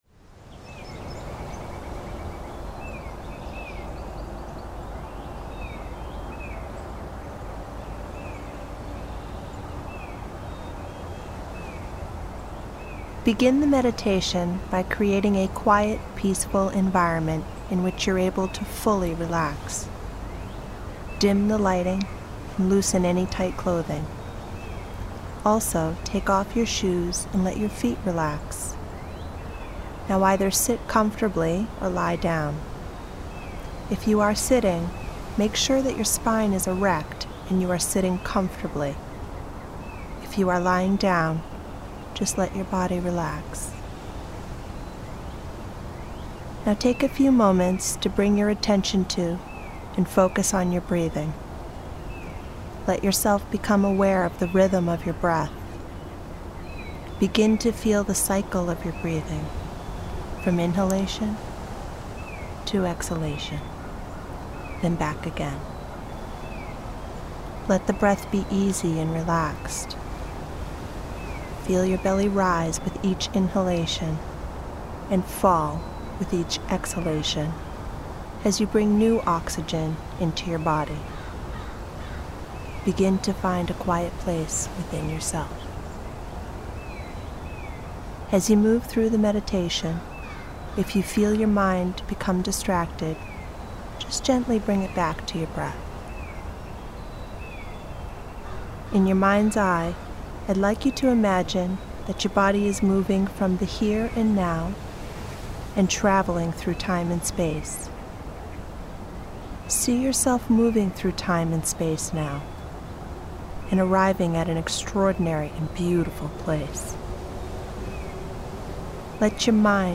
Creative Visualization Meditation